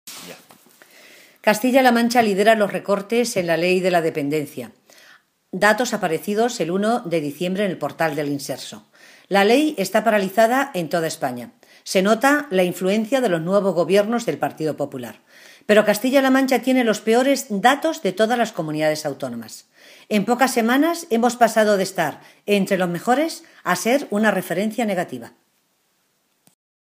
Matilde Valentín, portavoz en materia de Asuntos Sociales del Grupo Parlamentario Socialista
Cortes de audio de la rueda de prensa